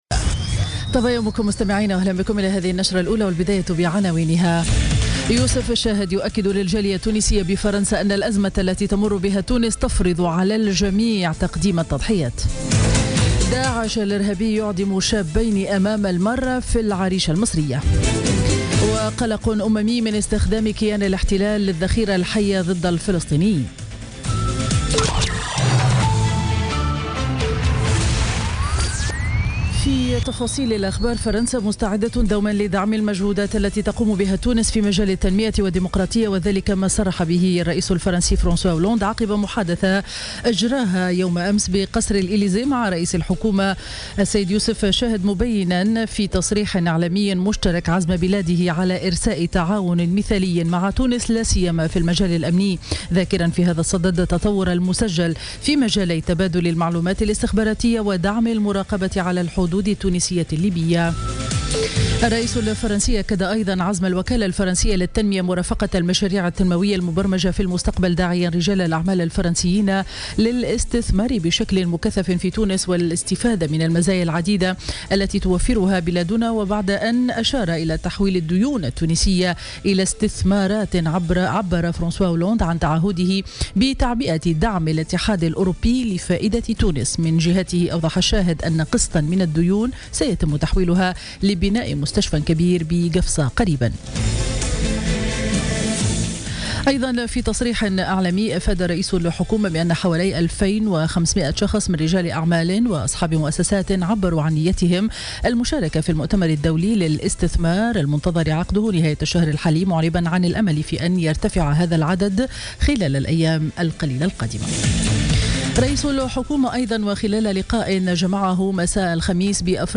نشرة أخبار السابعة صباحا ليوم الجمعة 11 نوفمبر 2016